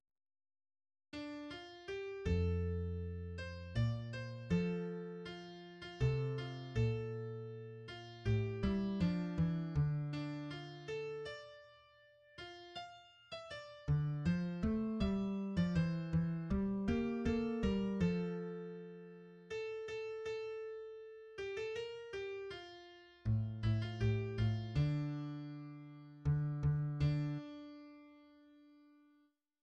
lyrics